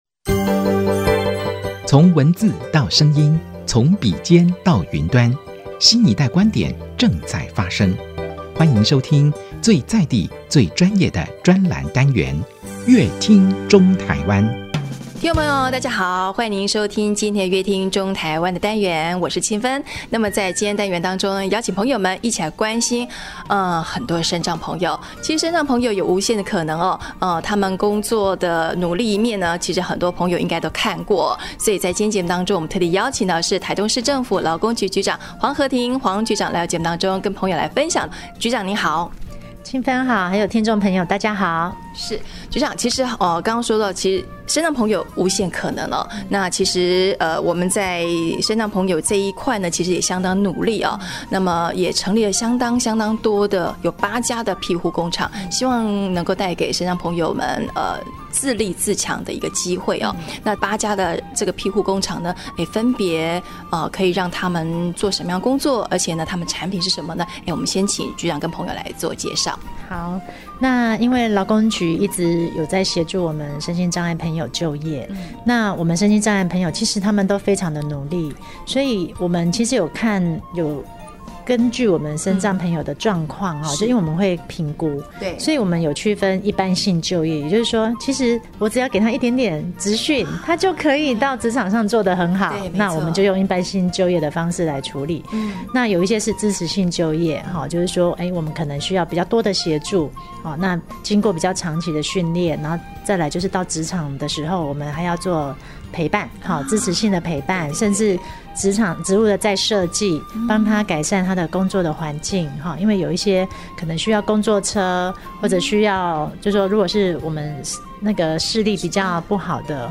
本集來賓：臺中市政府勞工局黃荷婷局長 本集主題：肯定身障庇護產品 勞工局推打卡集點抽機車 本集內容： 身障朋友